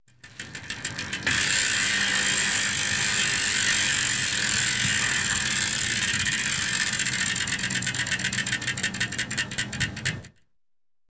Звуки велосипеда
Трещотка велосипеда проворачивается